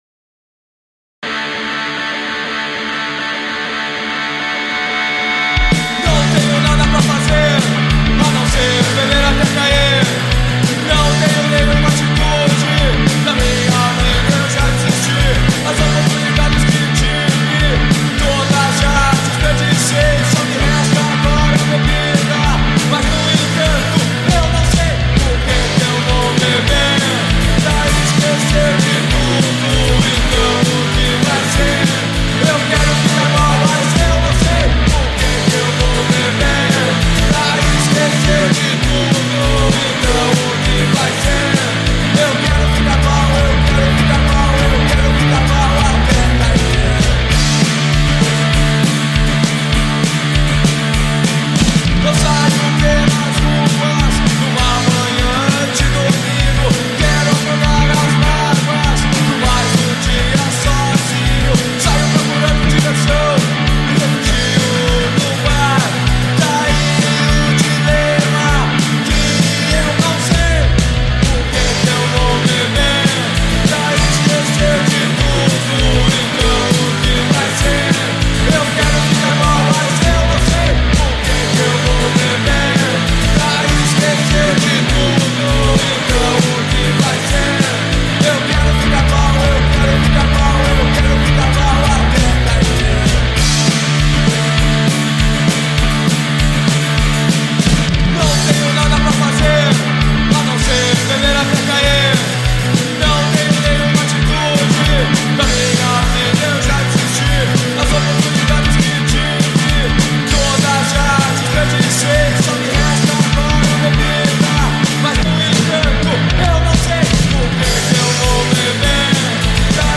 EstiloPunk Rock